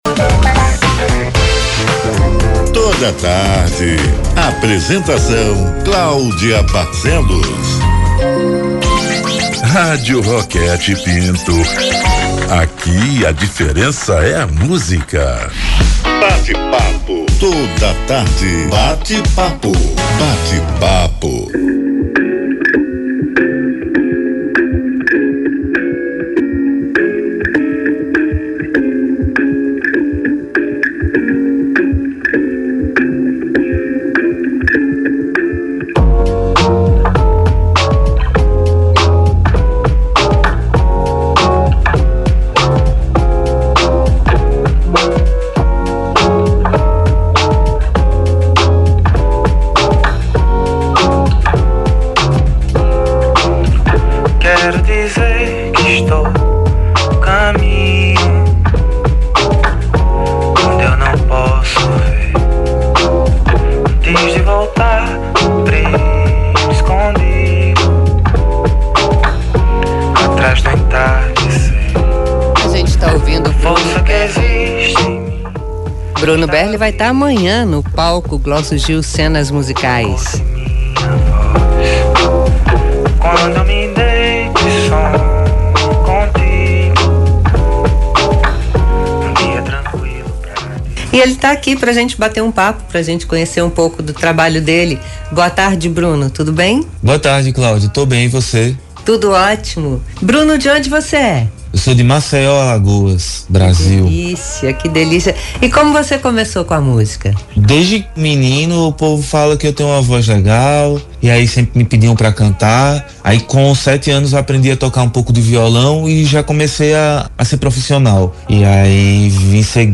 Hoje no bate-papo